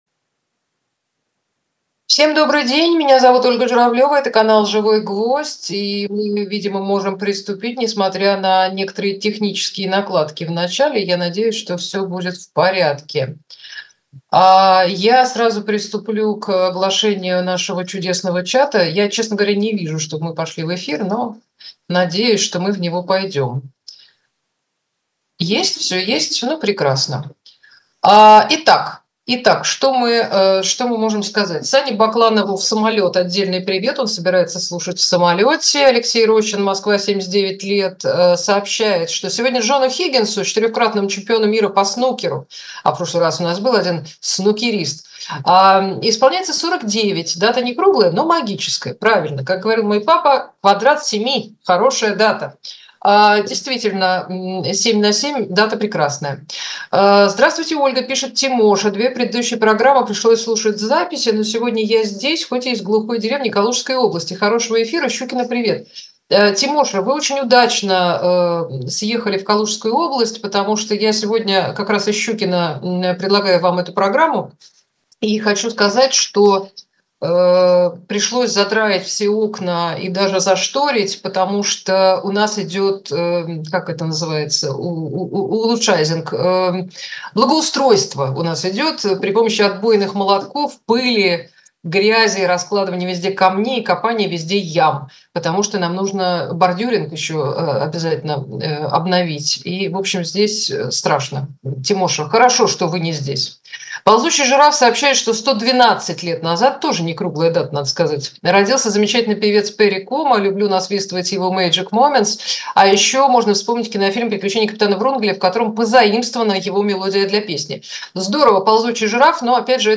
Ольга Журавлёва отвечает на ваши вопросы в прямом эфире